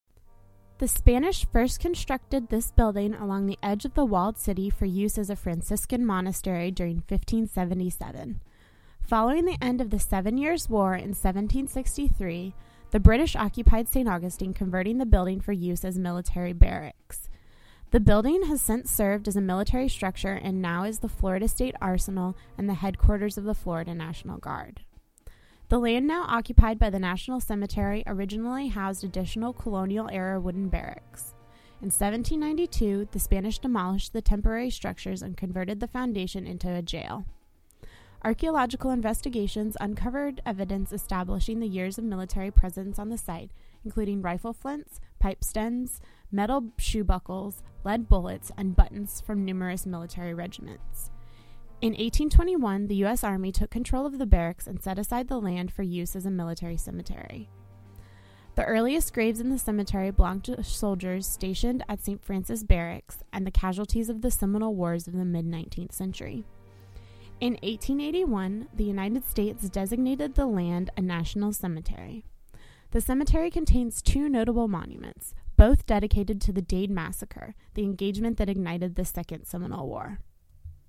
Researched, written, and narrated